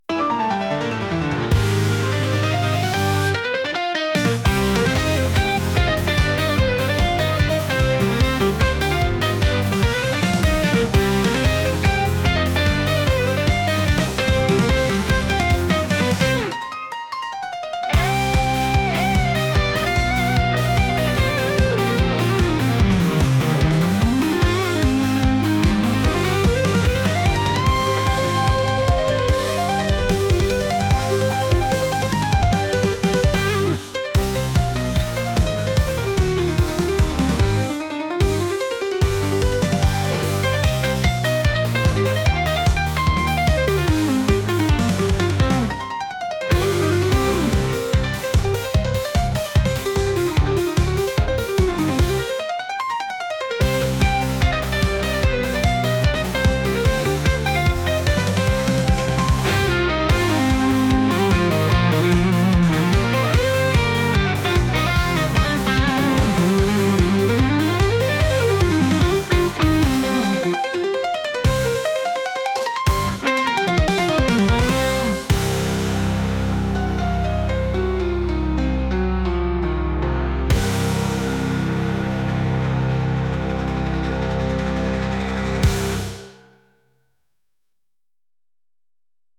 相手を追いかけるようなピアノ曲です。